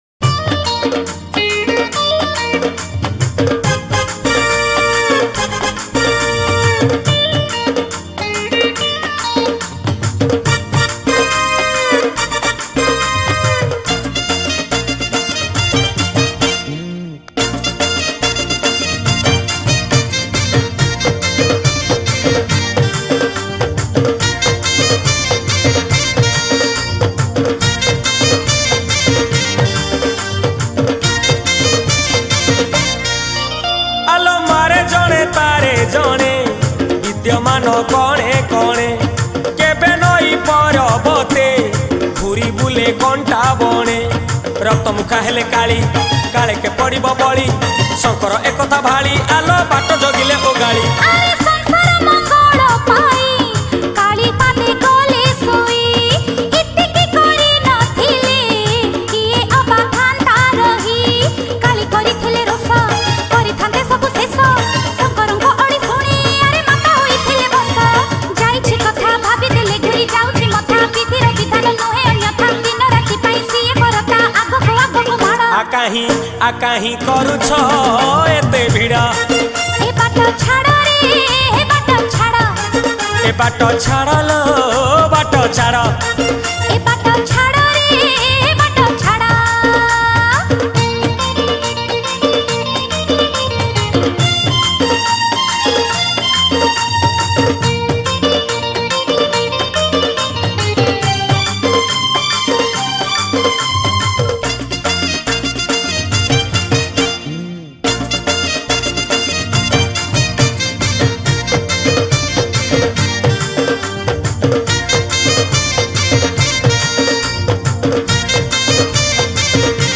Bolbum Special Song